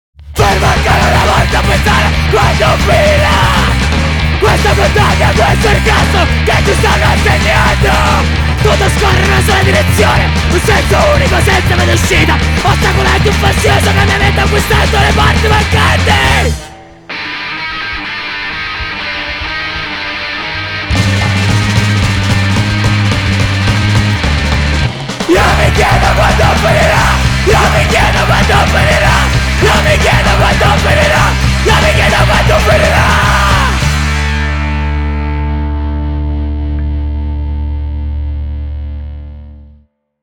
punk hardcore